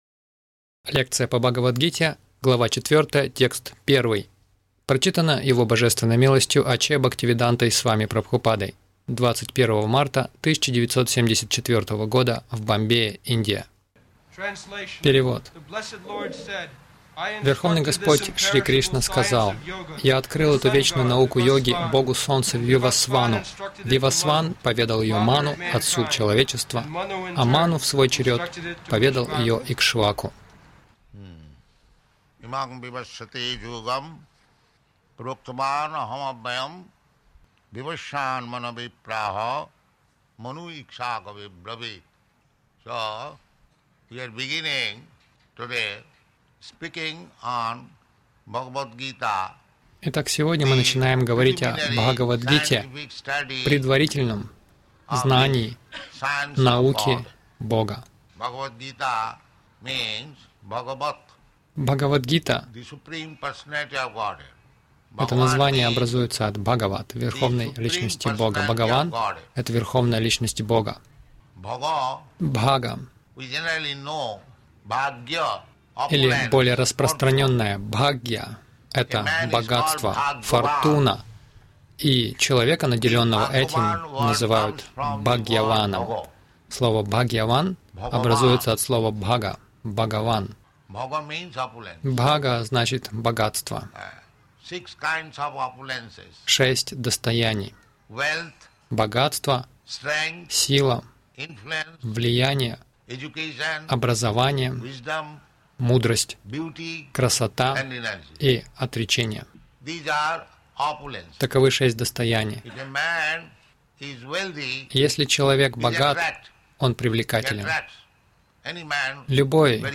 Милость Прабхупады Аудиолекции и книги 21.03.1974 Бхагавад Гита | Бомбей БГ 04.01 — Примите совершенное знание Загрузка...